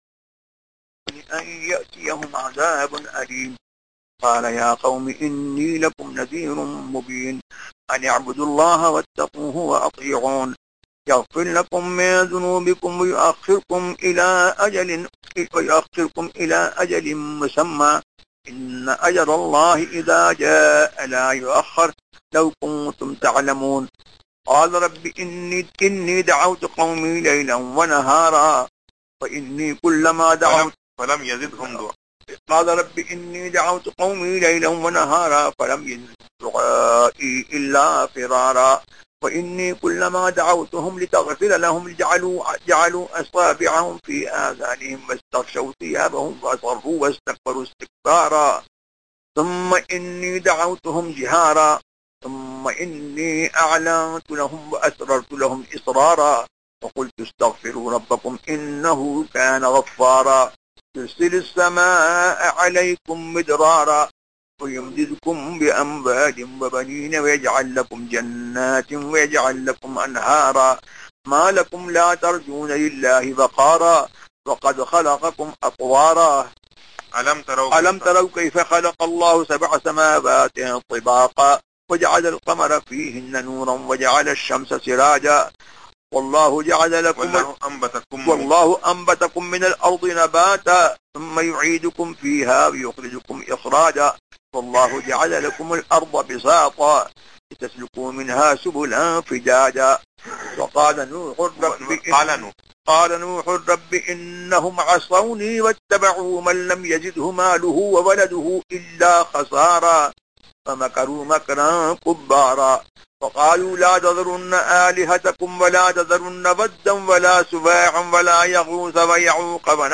Recitation from Surah Nuh to Surah Naziat
سورۃ نوح سے سورۃ نازعات تک تلاوت
recitation-from-surah-nuh-to-surah-naziat.mp3